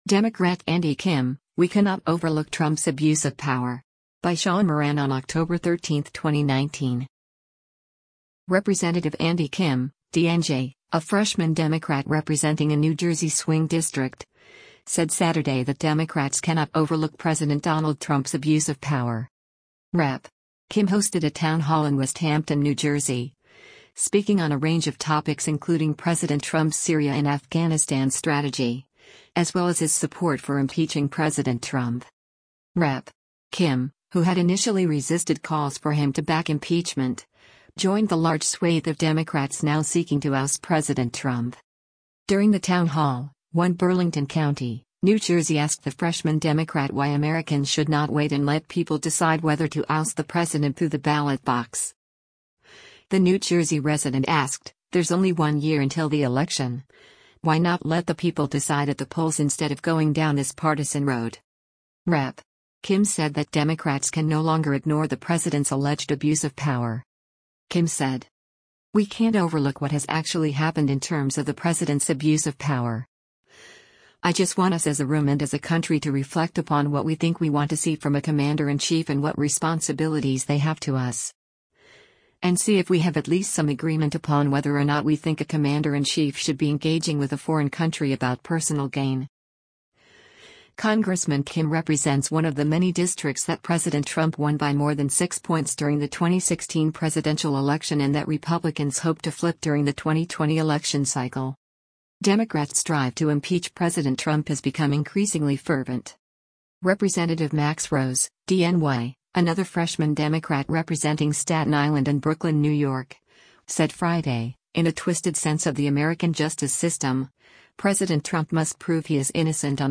Rep. Kim hosted a town hall in Westhampton, New Jersey, speaking on a range of topics including President Trump’s Syria and Afghanistan strategy, as well as his support for impeaching President Trump.